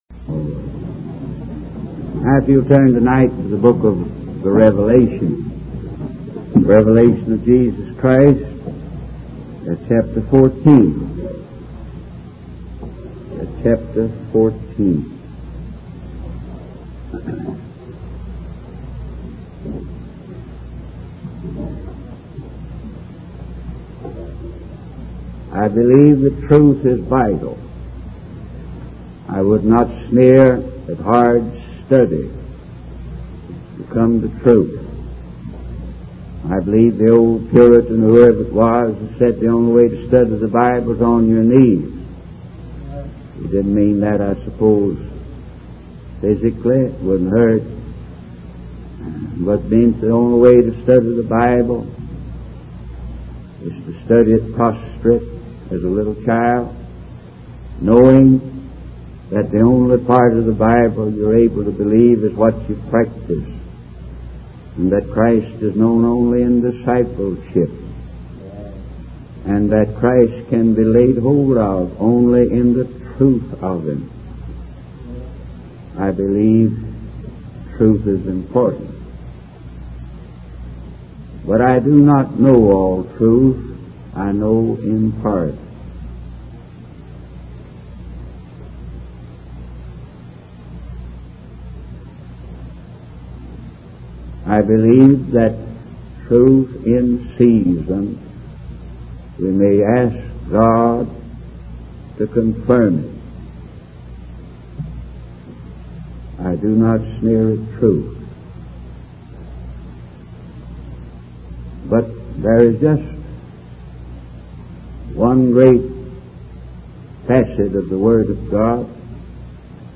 In this sermon, the preacher emphasizes the importance of the gospel in times of chaos and crisis.